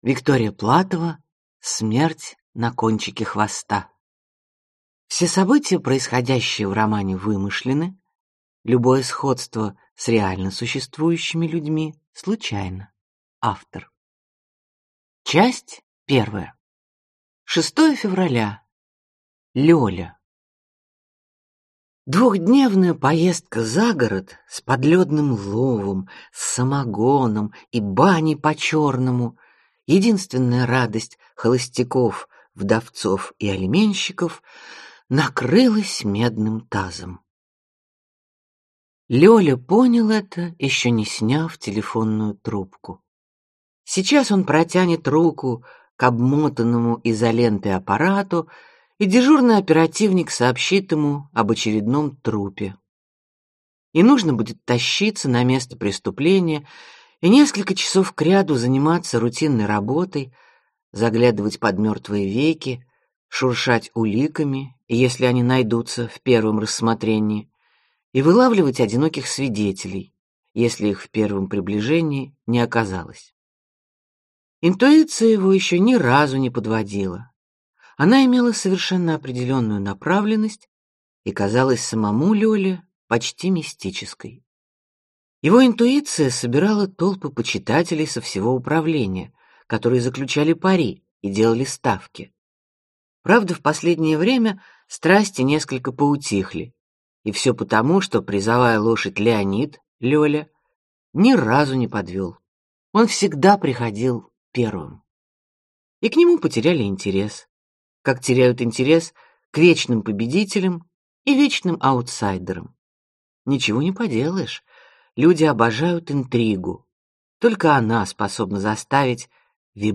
Аудиокнига Смерть на кончике хвоста | Библиотека аудиокниг